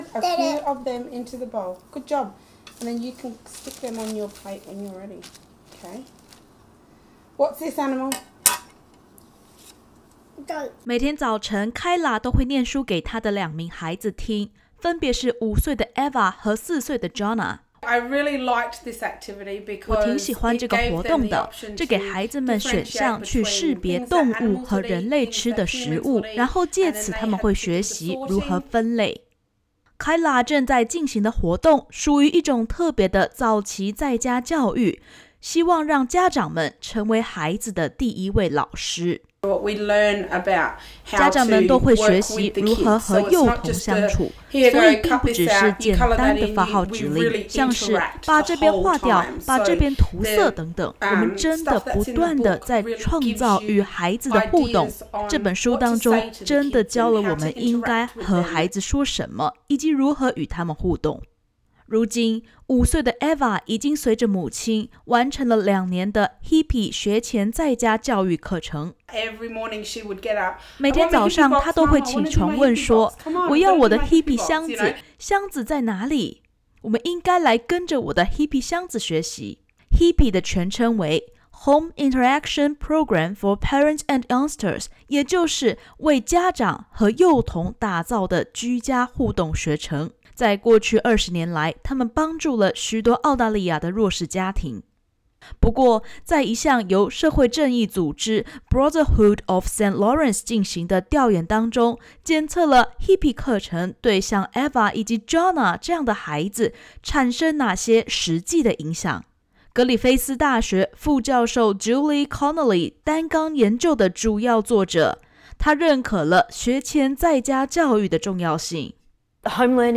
Hippy是一个倡导以家庭为中心的学前学习计画，他们最新发布了一项新的研究结果，证实幼儿的学前在家教育，能有效地帮助弱势家庭儿童，更加融入未来的学校生活。（点击首图收听采访音频）